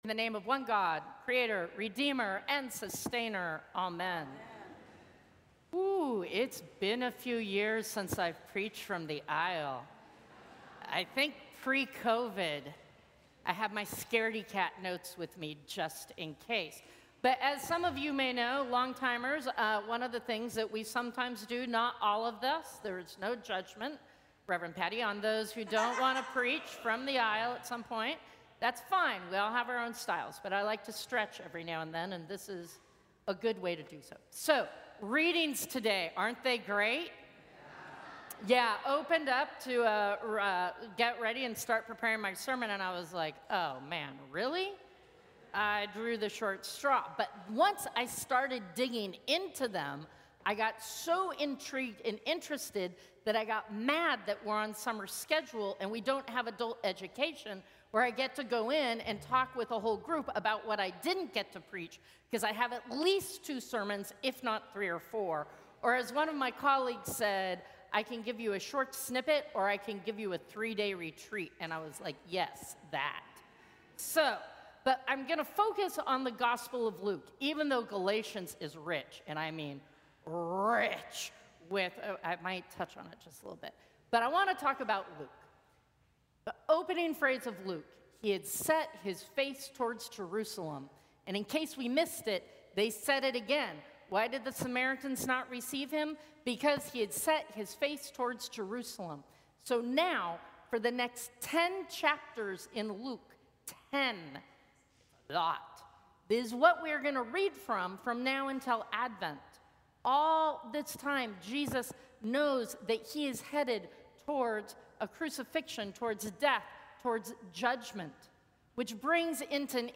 Sermons from St. Cross Episcopal Church Third Sunday after Pentecost Jun 29 2025 | 00:13:29 1x 00:00 / 00:13:29 Subscribe Share Apple Podcasts Spotify Overcast RSS Feed Share Link Embed